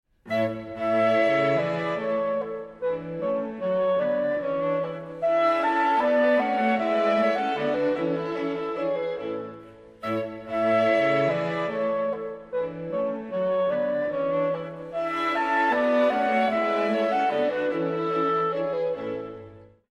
Works for Clarinet
Super Audio CD